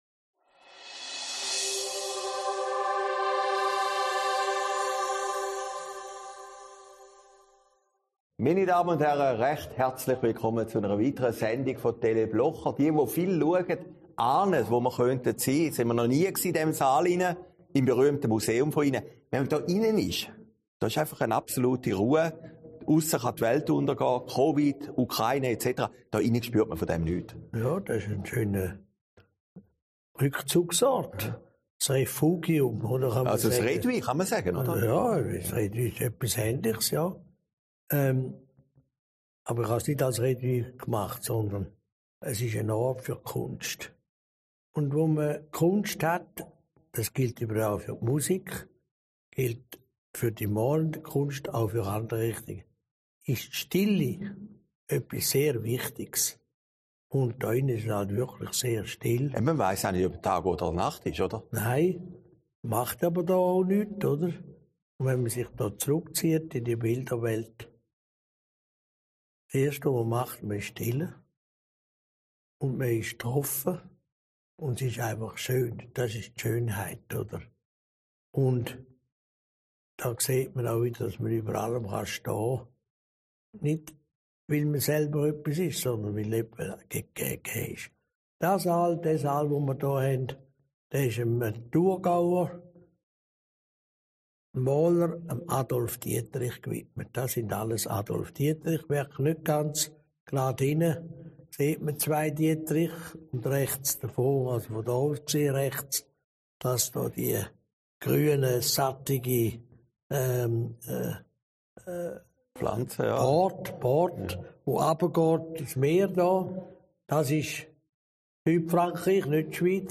Sendung vom 18. März 2022, aufgezeichnet in Herrliberg